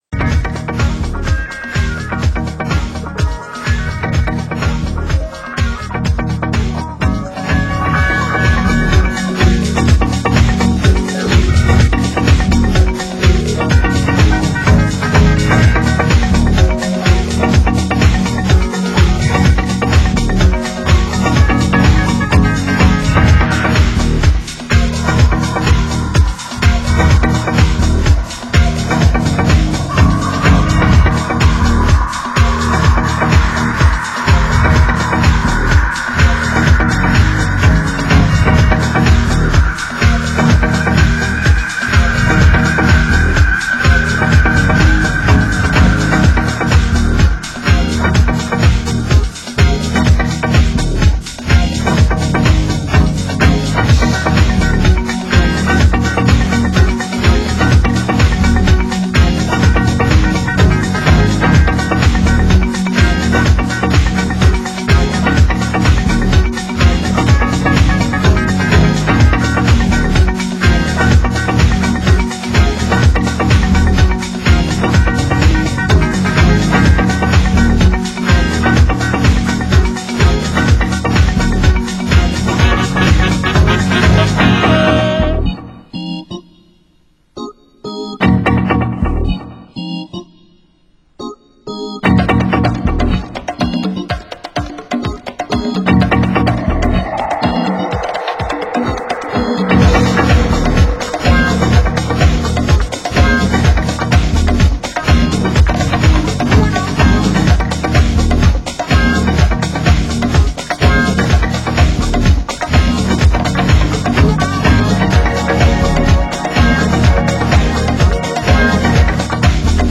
Genre: Deep House